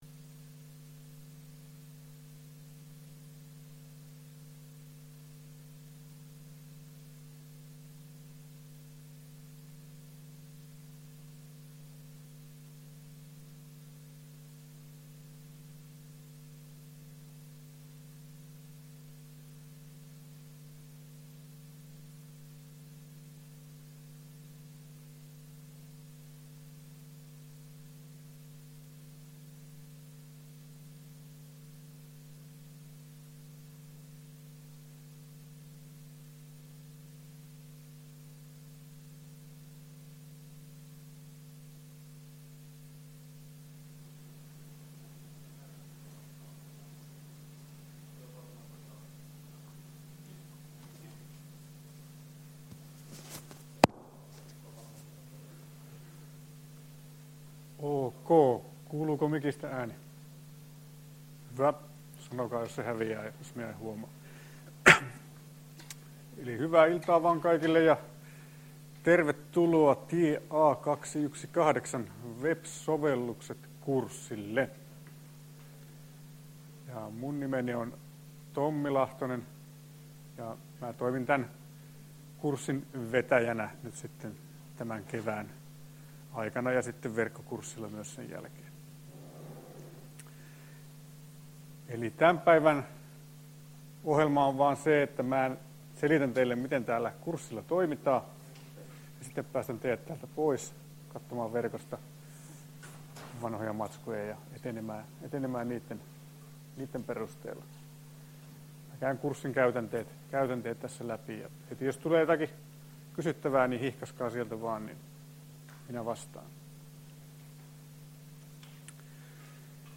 Luento 10.1.2017. — Moniviestin